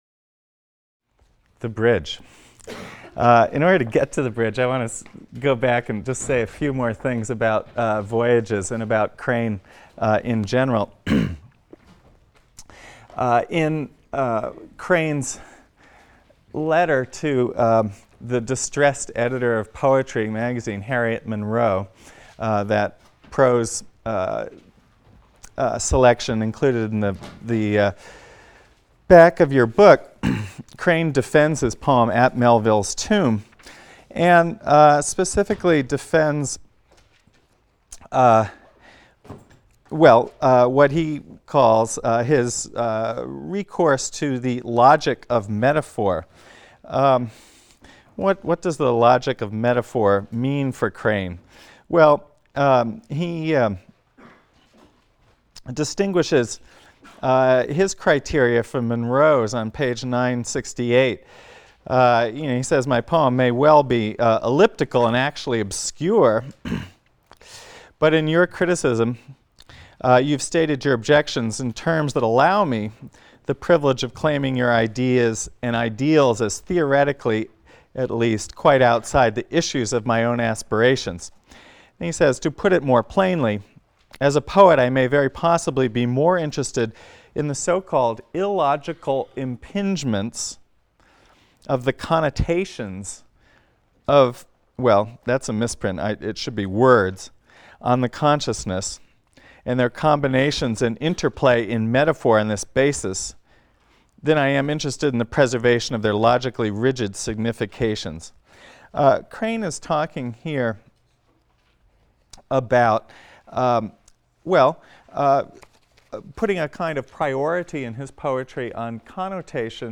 ENGL 310 - Lecture 14 - Hart Crane (cont.)